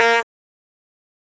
honk.ogg